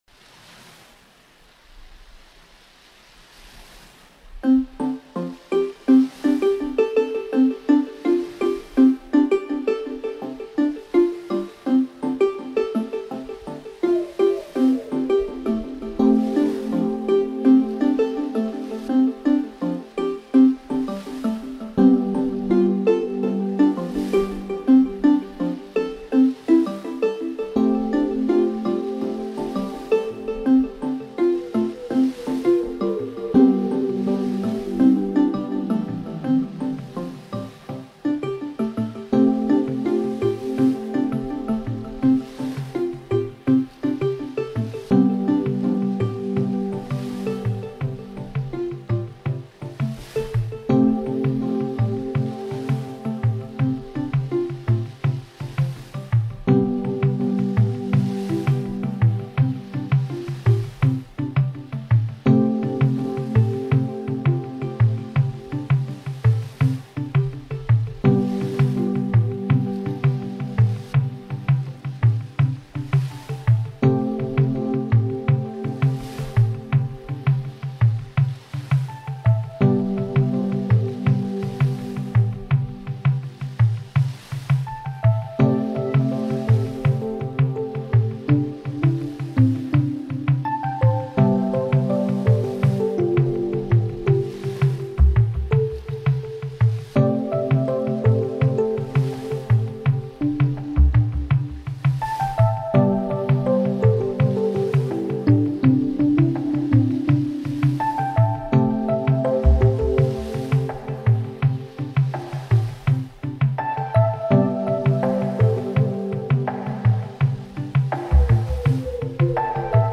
aquatic ambience, buoyant electronics and tidal rhythms
Ambient